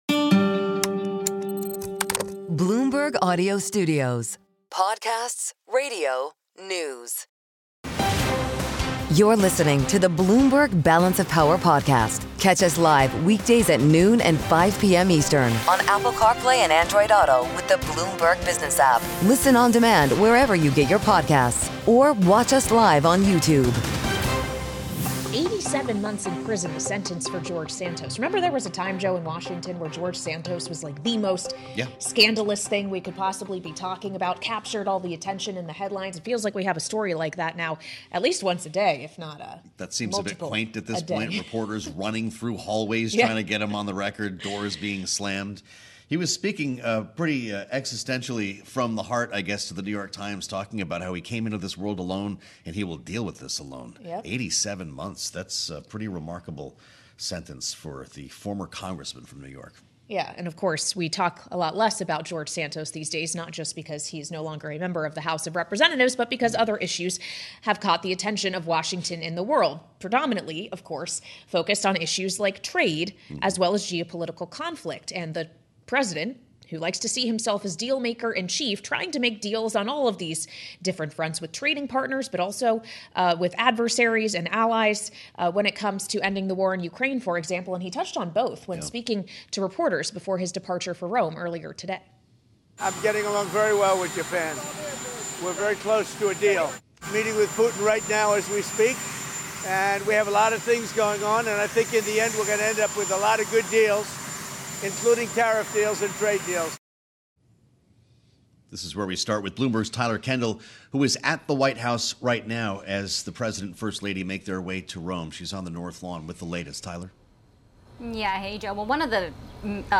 including conversations with influential lawmakers and key figures in politics and policy.